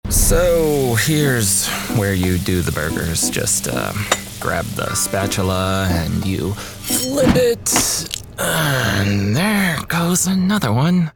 Animation
Soprano